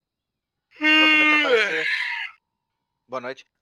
Bocejo